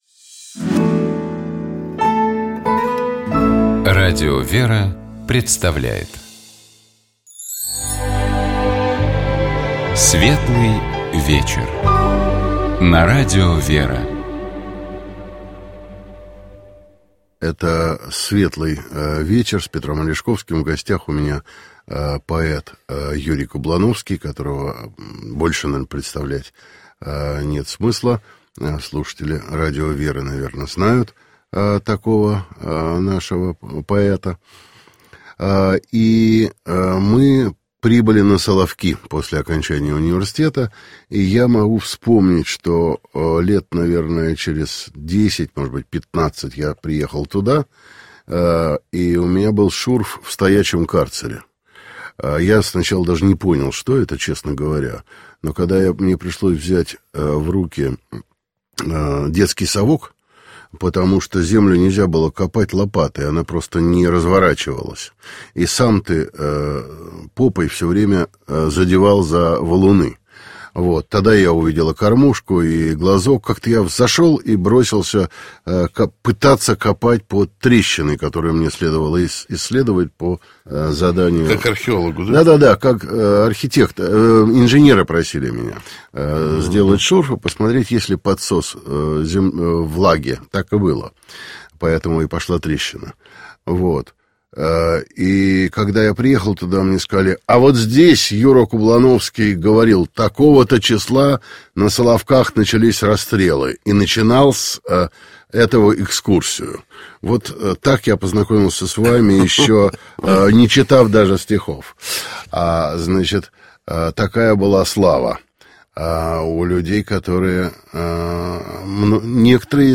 В гостях у Петра Алешковского был поэт, публицист, искусствовед Юрий Кублановский.